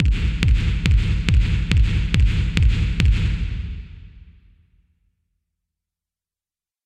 One split goes right back to the mixer, the other to a separate channel, via a RV7000 reverb unit.
At the moment it sounds pretty terrible!